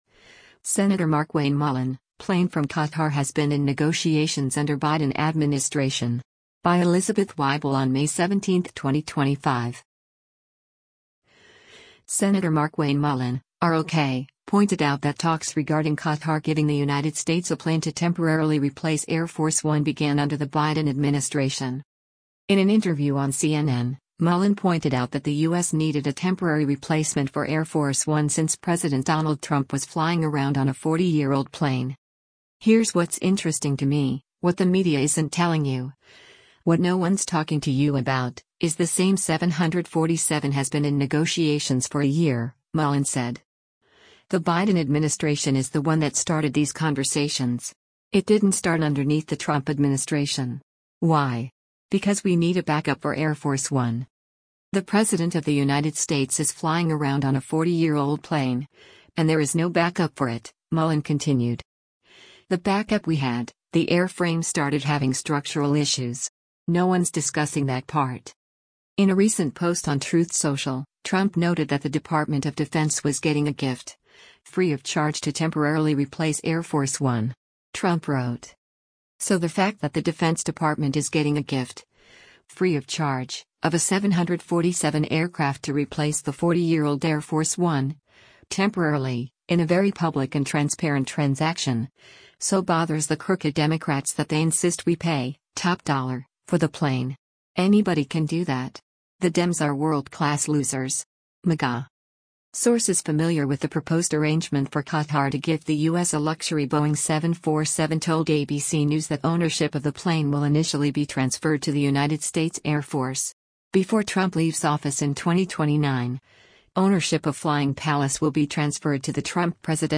In an interview on CNN, Mullin pointed out that the U.S. needed a temporary replacement for Air Force One since President Donald Trump was “flying around on a 40-year-old plane.”